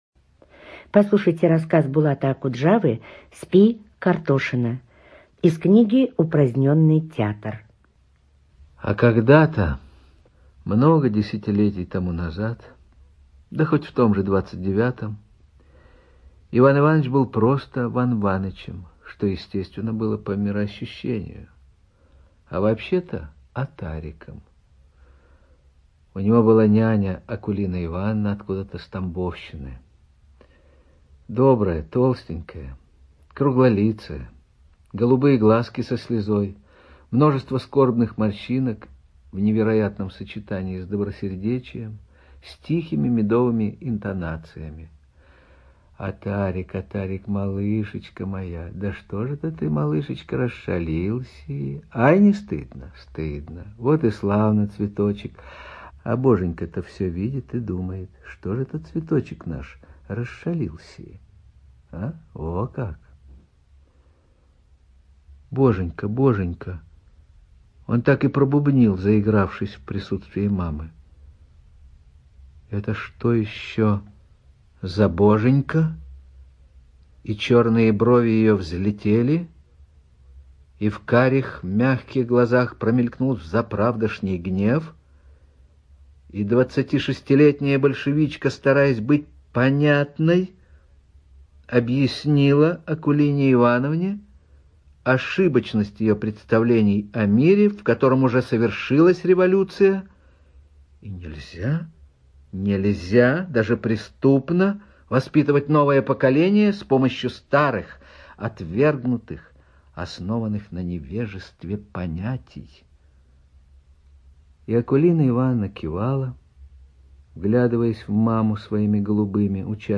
Студия звукозаписиРостовская областная библиотека для слепых